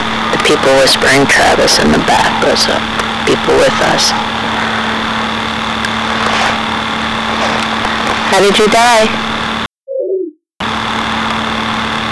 These recordings were taken using a General Electric Microcassette Recorder, Model #3-5326A using a Radio Shack MC-60 Microcassette at normal speed.
"How did you die?" Answer slowed with noise reduction using Audacity.